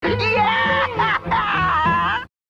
Wizard Crying Meme